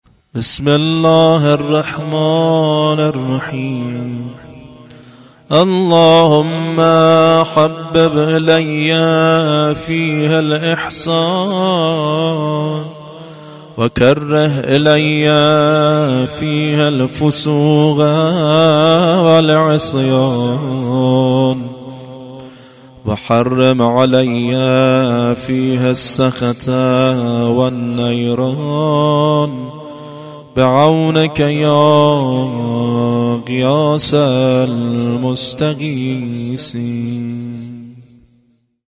دعای ایام ماه مبارک رمضان